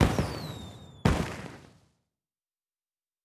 Firework (3).wav